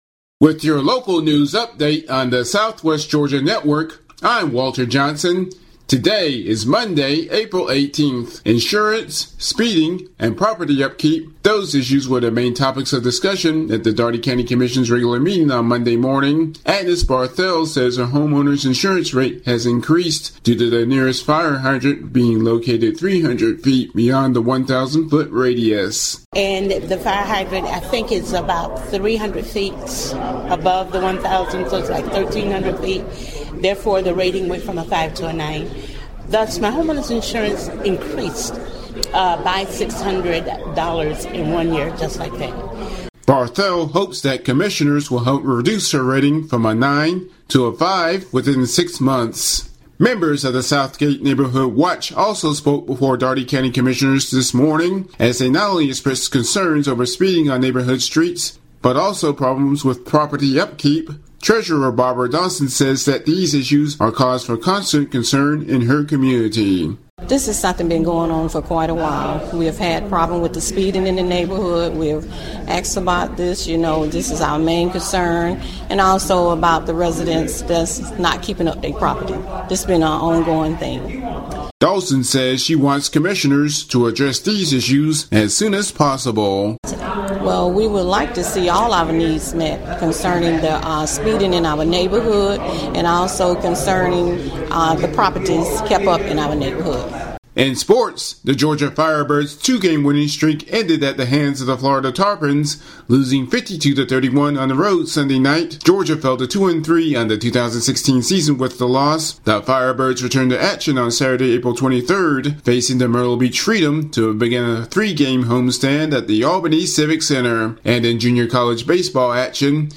Audio News Updates (Pilot #2)